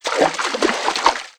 MISC Water_ Splash 05.wav